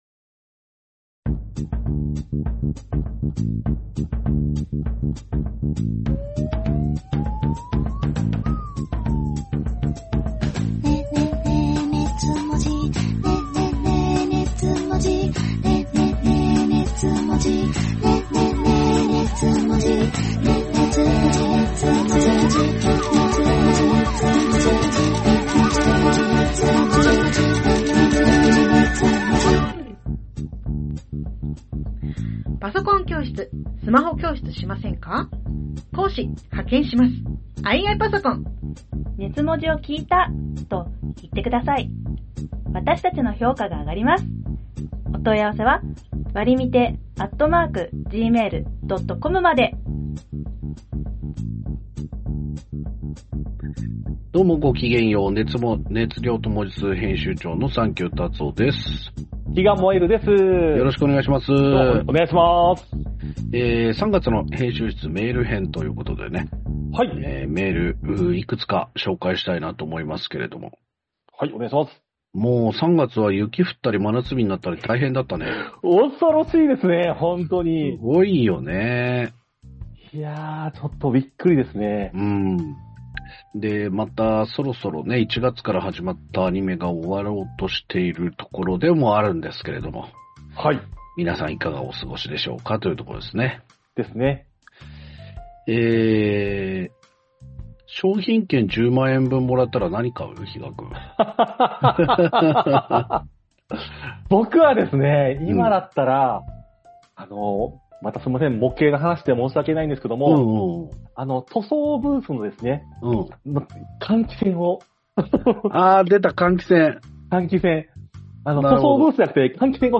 オタク芸人 サンキュータツオ Presents 二次元を哲学するトークバラエティ音声マガジン『熱量と文字数』のブログです。